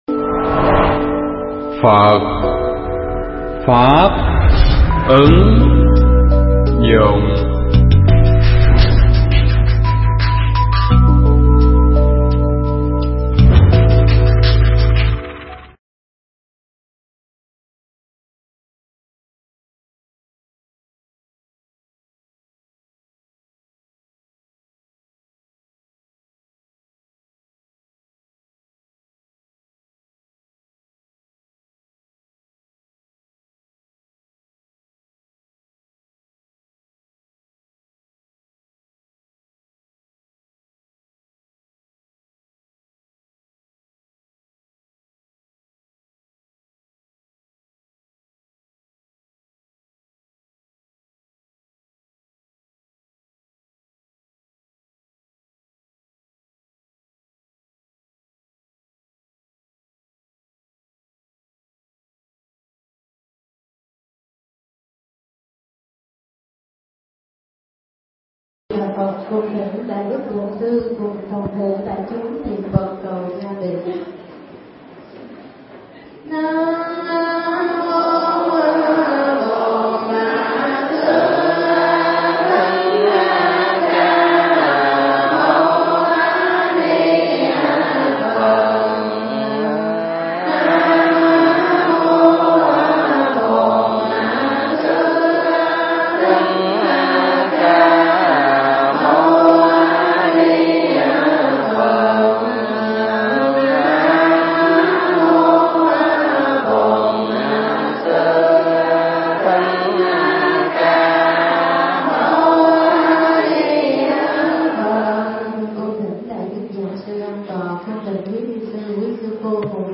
Tải mp3 Thuyết pháp Tu Thân Và Tu Tâm
giảng tại chùa Bồ Đề Lan Nhã